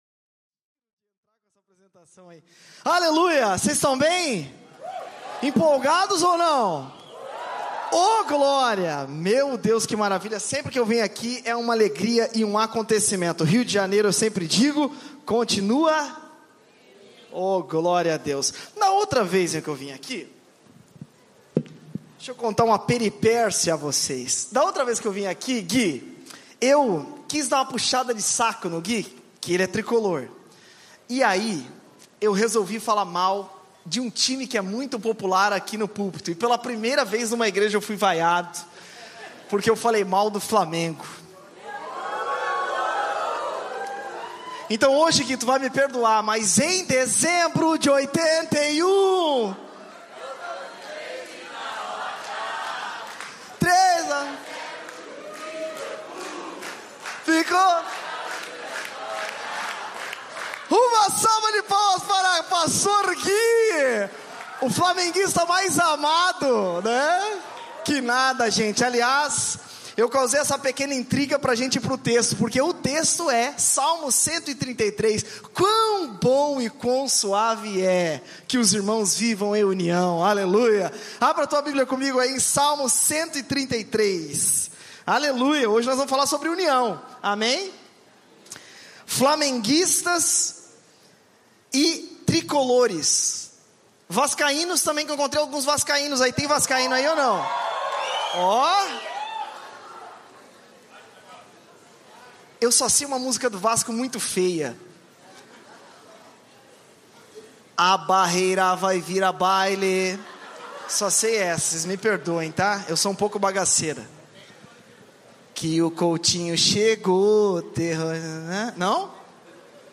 YouthCon 2026 #1